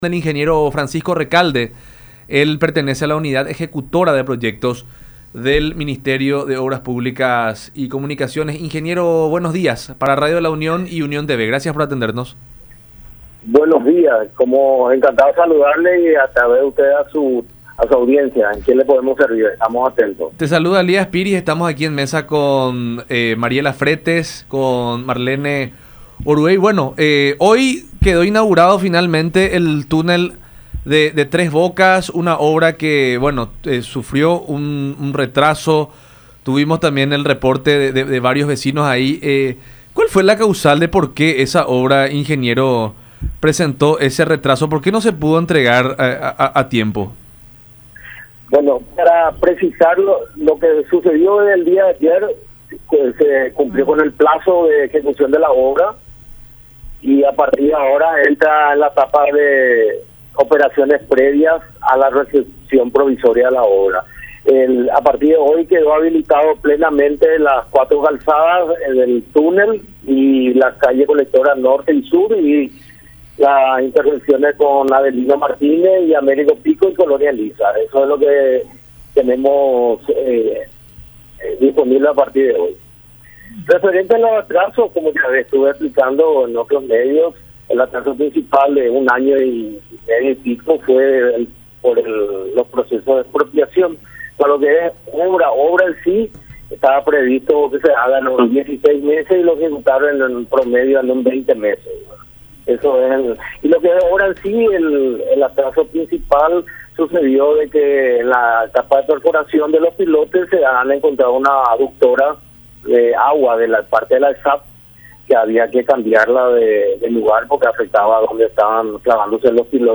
en diálogo con La Unión Hace La Fuerza a través de Unión TV y radio La Unión